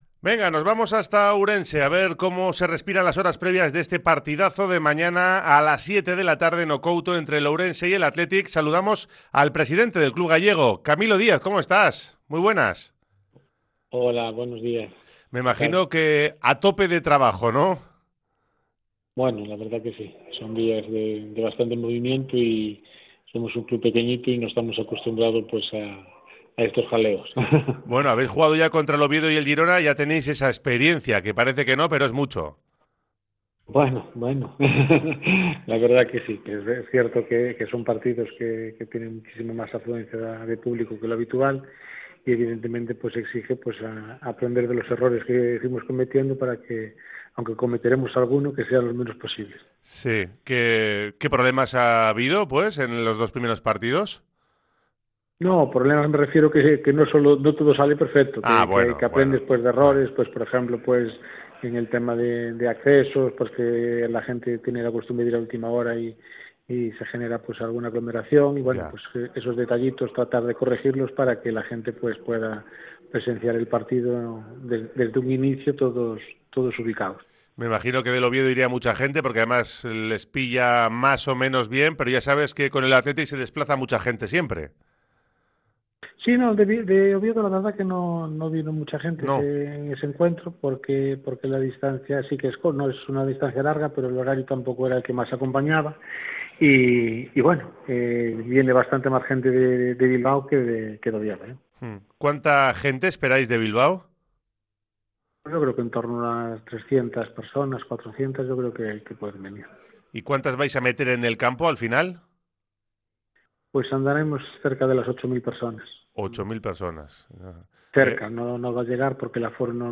PRESIDENTE-OURENSE.mp3